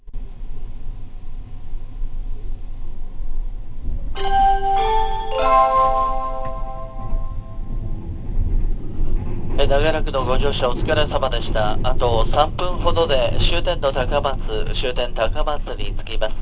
・285系車内チャイム・放送（更新後）
【中間駅・肉声放送用】（16秒：91.2KB）
とはいえ、始終着でも肉声で放送する場合は中間駅用のチャイムが流れてしまうようです。